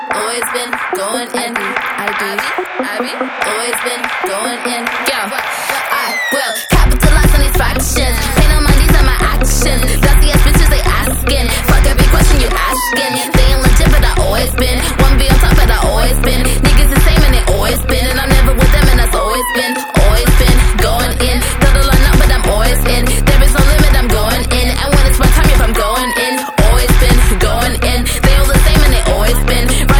la pop, le garage anglais, le hip-hop oldschool, et la trap